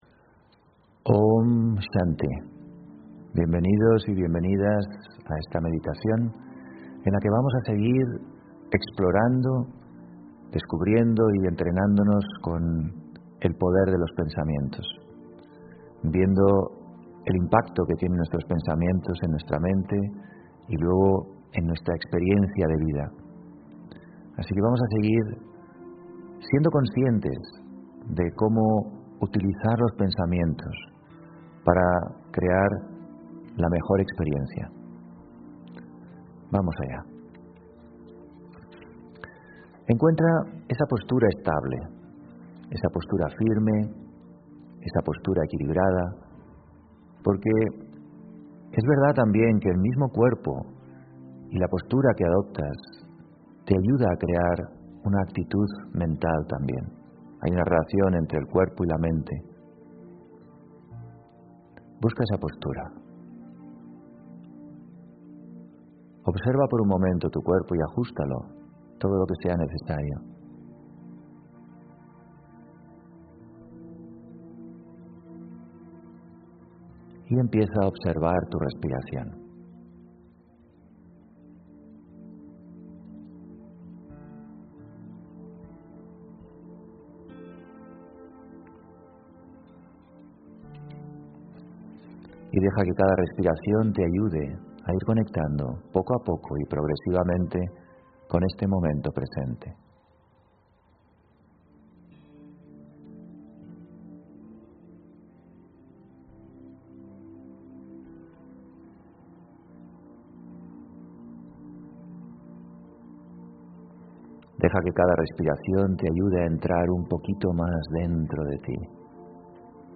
Meditación Raja yoga y charla: Respeta, comprende y suelta (18 Diciembre 2020) On-line d ...